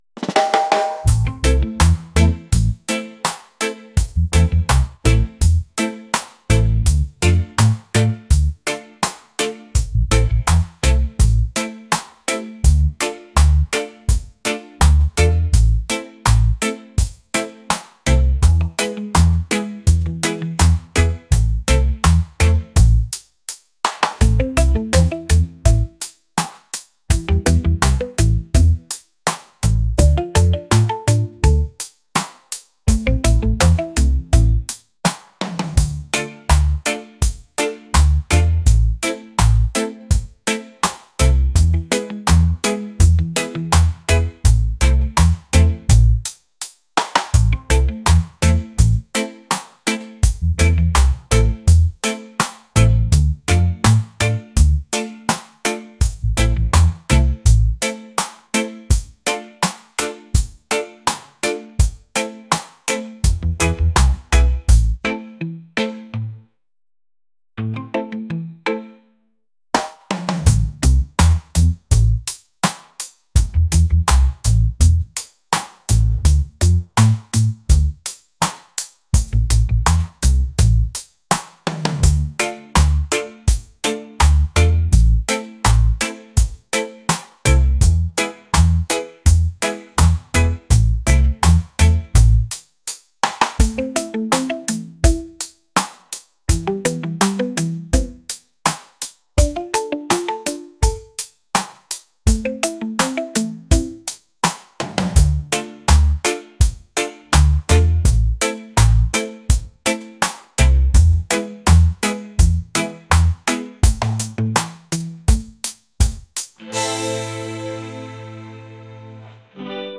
reggae | lounge | soul & rnb